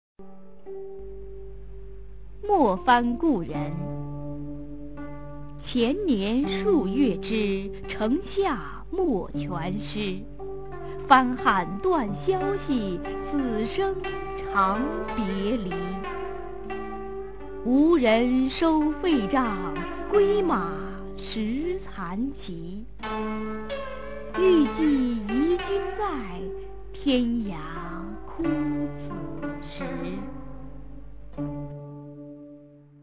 [隋唐诗词诵读]张籍-没蕃故人a 配乐诗朗诵